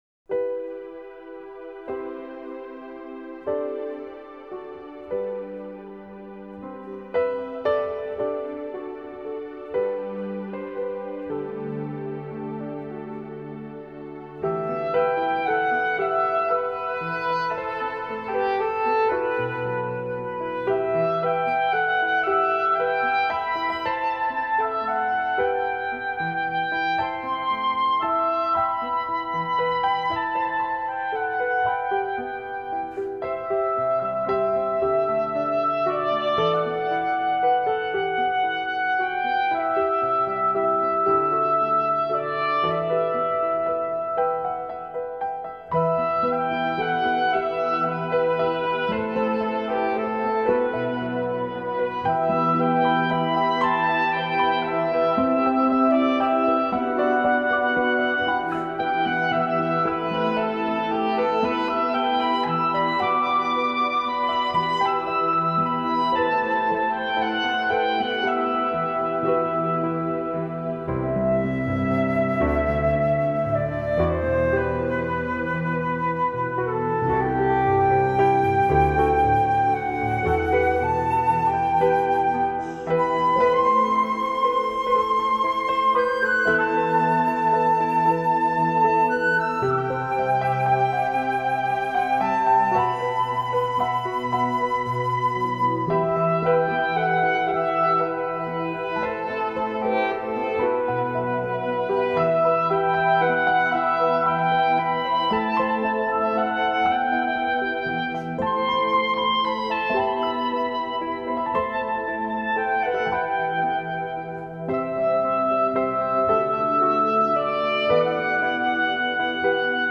clarinet
cello
flute
tabla and additional frame drums